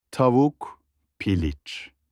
تلفظ مرغ به ترکی در این حالت بسیار ساده است: “تاووک” با تاکید روی هجای اول.
chicken-in-turkish.mp3